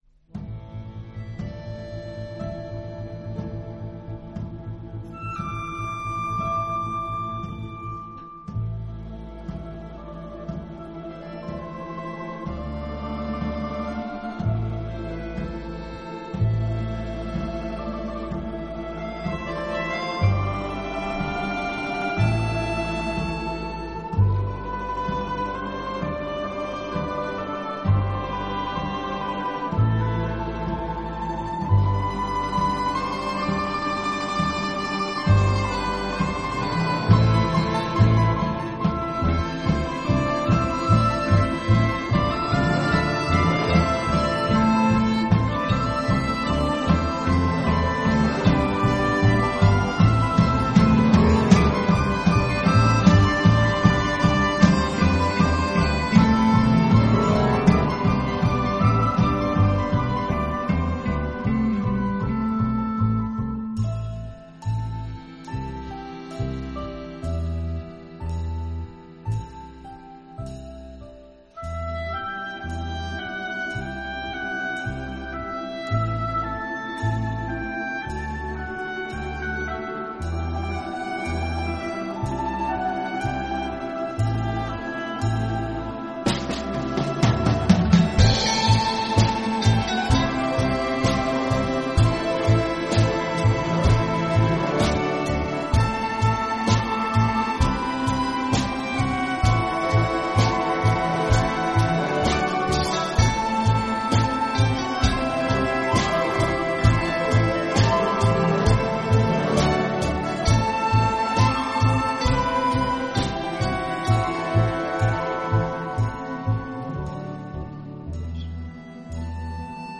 Произведение создано из мотивов ненецких народных песен.